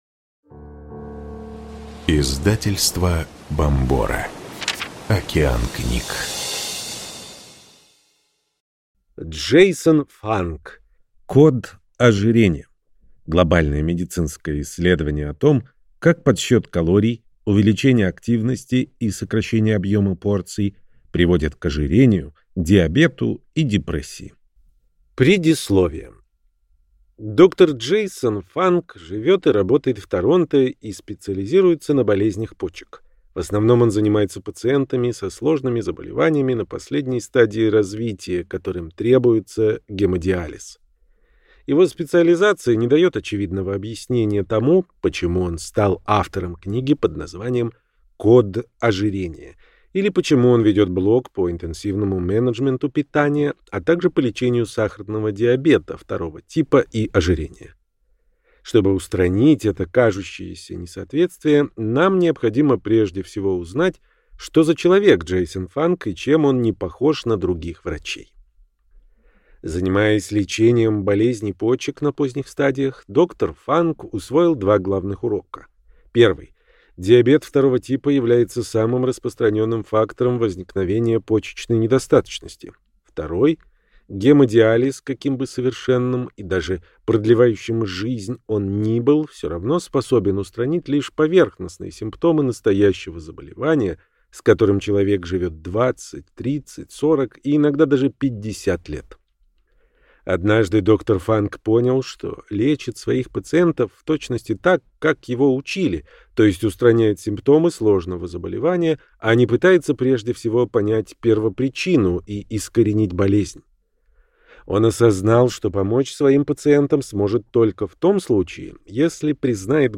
Аудиокнига Код ожирения.